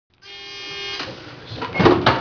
buzzer.WAV